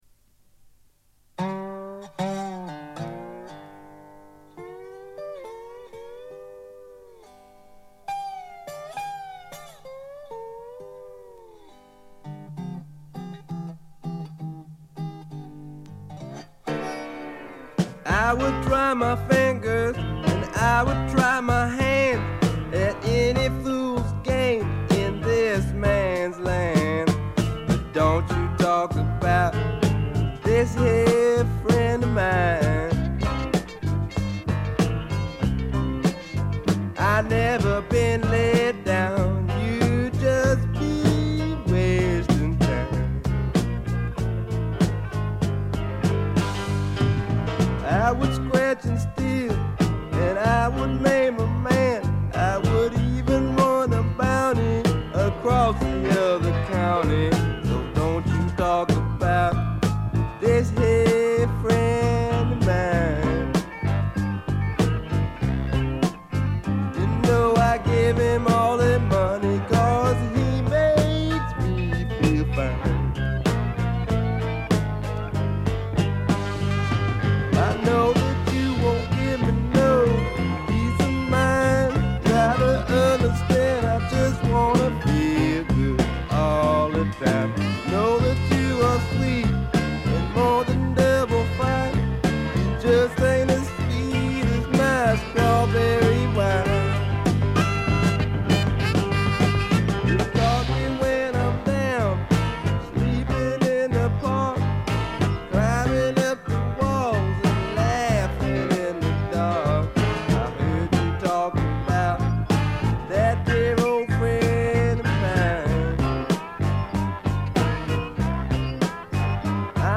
ほとんどノイズ感無し。
いうまでもなく米国スワンプ基本中の基本。
試聴曲は現品からの取り込み音源です。